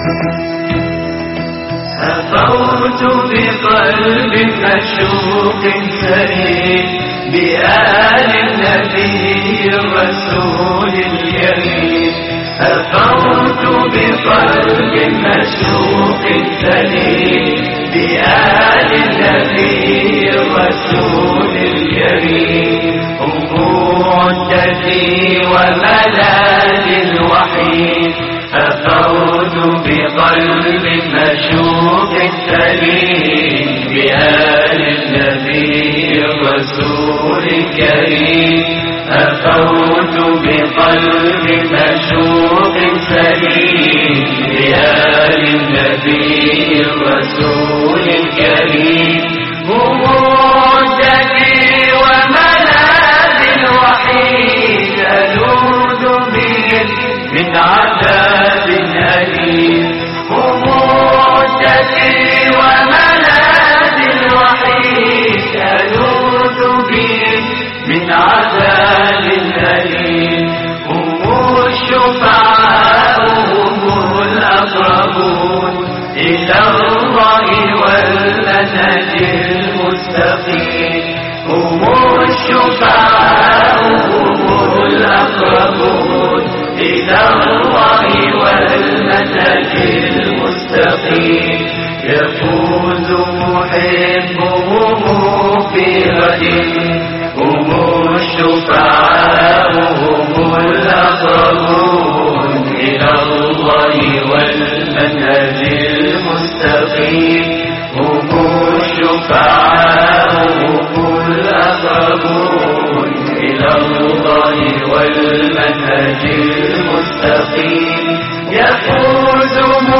هفوت بقلب ـ مقام البيات - لحفظ الملف في مجلد خاص اضغط بالزر الأيمن هنا ثم اختر (حفظ الهدف باسم - Save Target As) واختر المكان المناسب